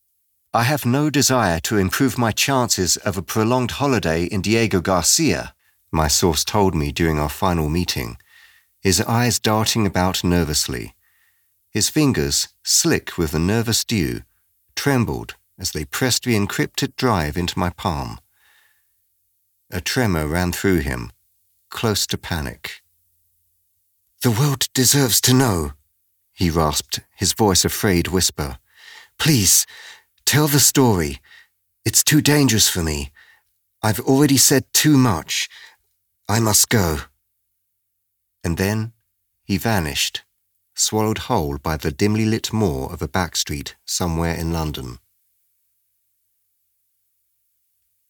Audio Book Voice Over Narrators
Adult (30-50) | Older Sound (50+)